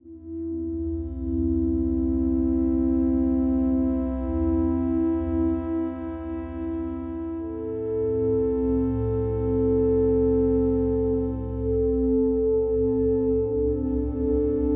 真实的和生成的 " 相位梁01
只是修改了"Fruity Kick"插件，并用大量的滤波器、相位和变调效果对其进行了修改。
标签： 能源BeamShots 外星人 武器 激光 相位
声道立体声